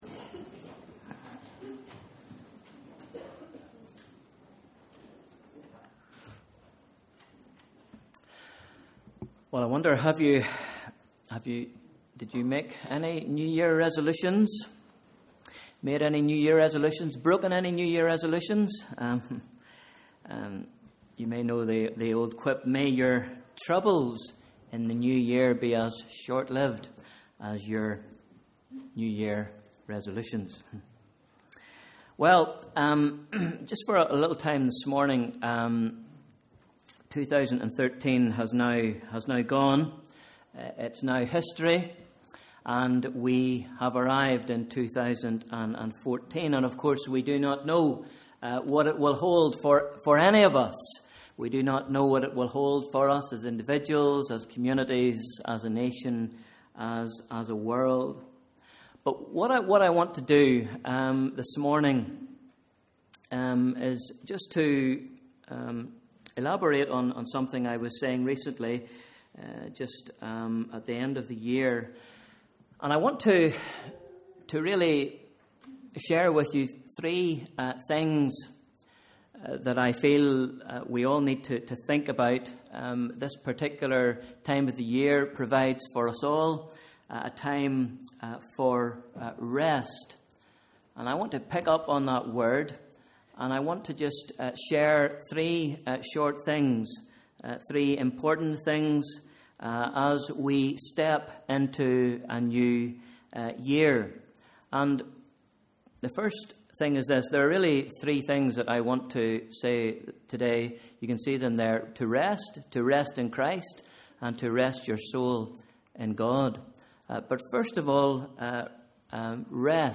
Sunday 5th January 2014 – Morning Service